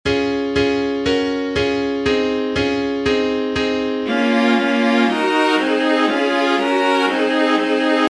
Here are the basic harmonies of the 1a song!
1aharmonies.mp3